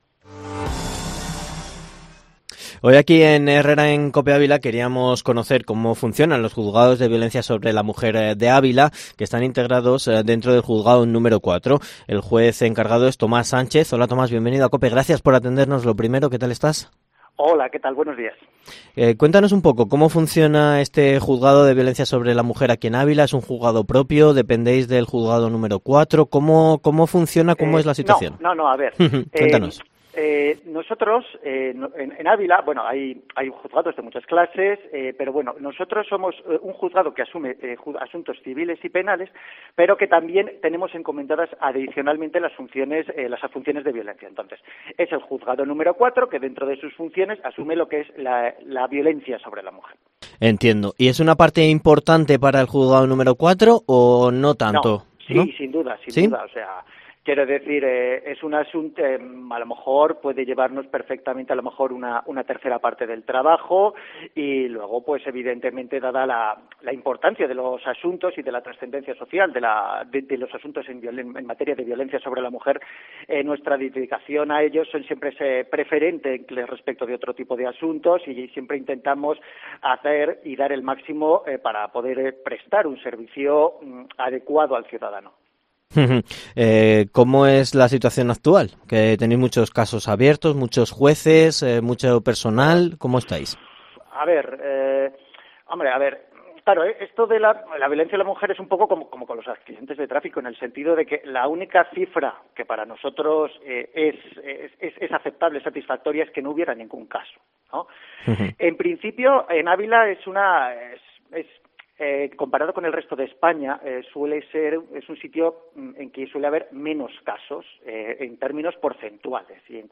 Entrevista con el Juez Tomás Sánchez en Herrera en COPE Ávila